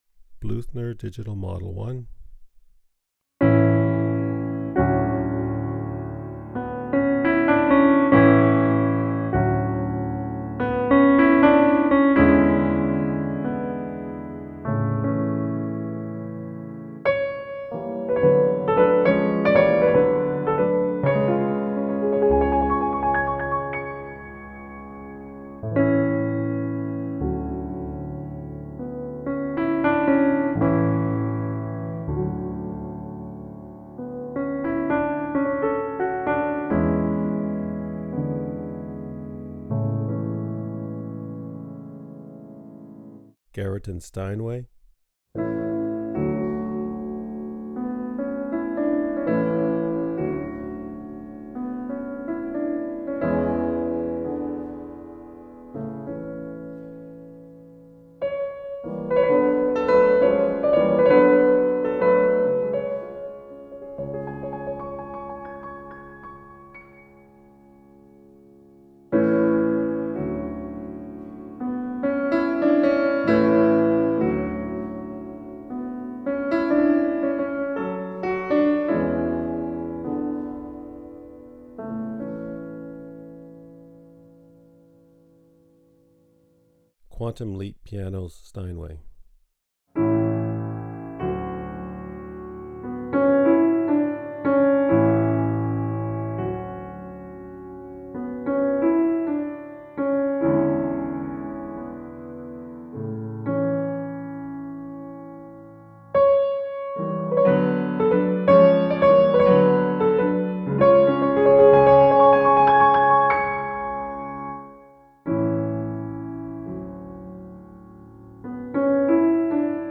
The same passages were also played back on 6 digital piano sampled libraries The Bluthner Digital Model One, Garritan's Steinway, Quantum Leap Pianos, Ivory (Bosendorfer and German D), Pianoteq, and Galaxy (Bosendorfer & Steinway).
piano-libraries-pp.mp3